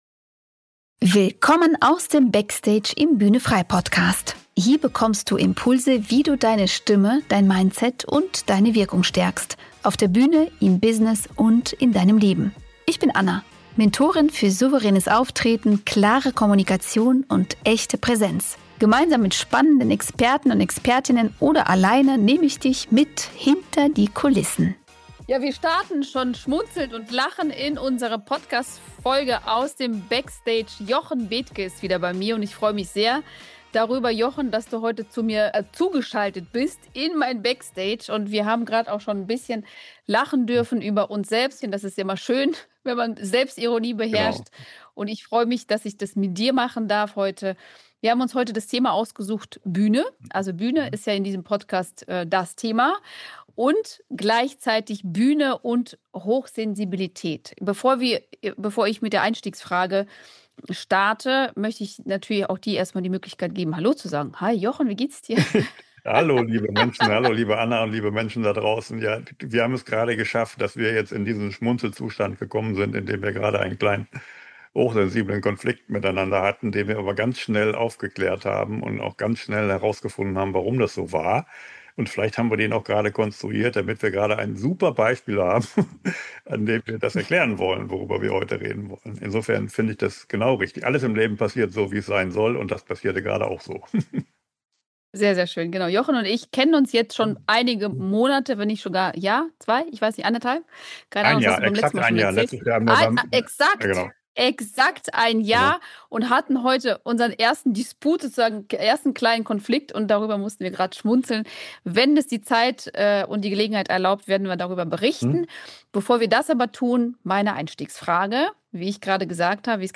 Expertengespräch